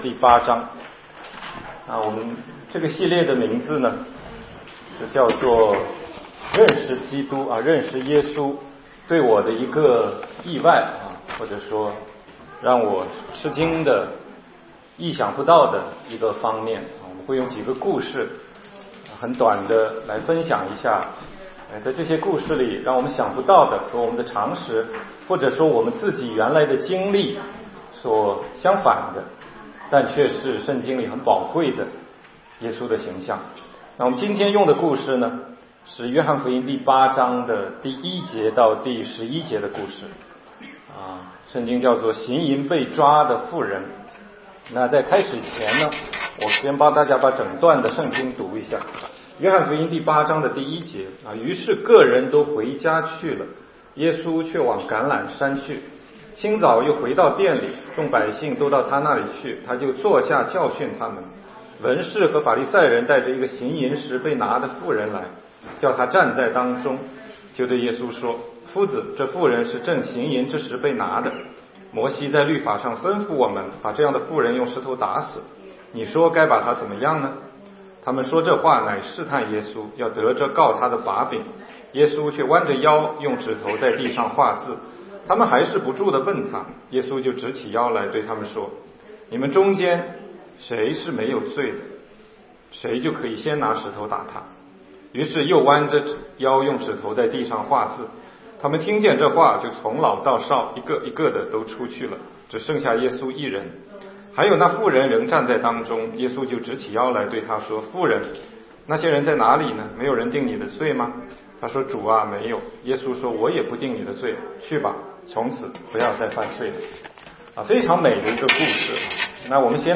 16街讲道录音 - 出人意料的耶稣—行淫被抓的妇人